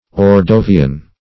ordovian - definition of ordovian - synonyms, pronunciation, spelling from Free Dictionary Search Result for " ordovian" : The Collaborative International Dictionary of English v.0.48: Ordovian \Or*do"vi*an\, a. & n. (Geol.)